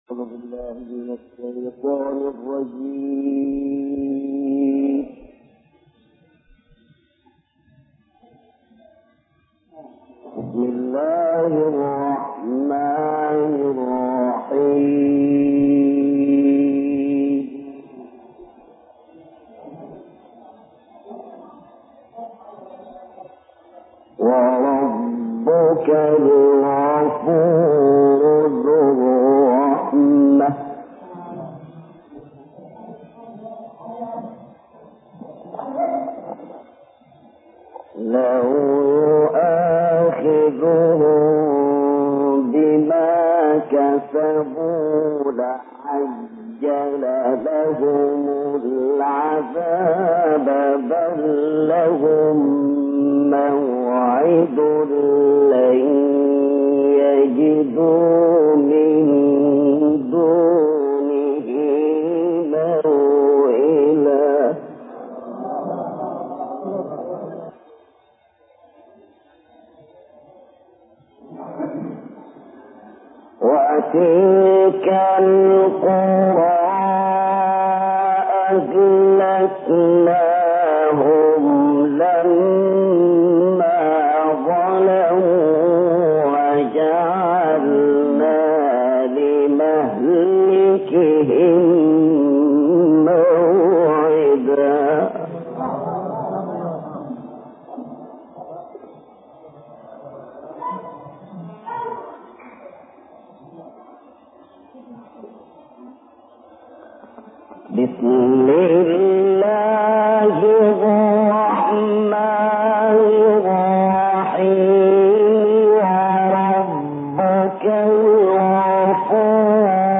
pagbasa ng Quran